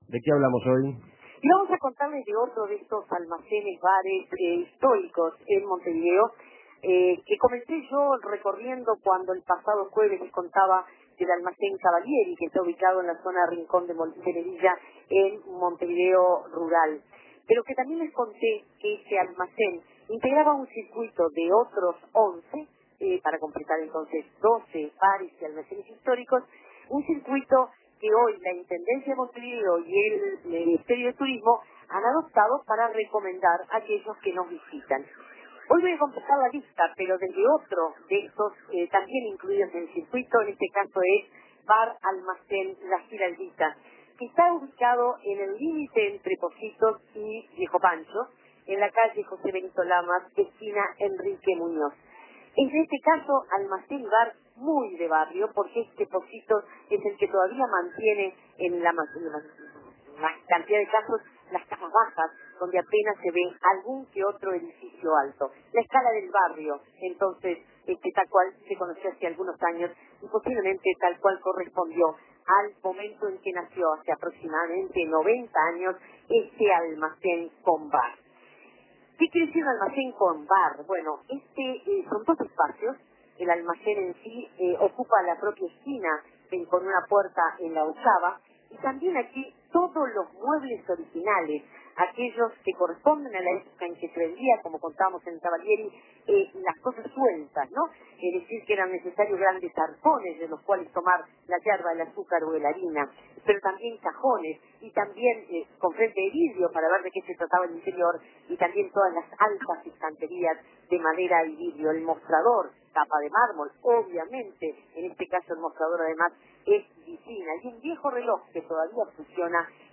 Una visita al histórico Bar Almacén La Giraldita, en Benito Lamas y Enrique Muñoz.